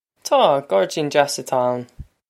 Pronunciation for how to say
Taw. Gawr-deen jass a-taw ow-n.
This is an approximate phonetic pronunciation of the phrase.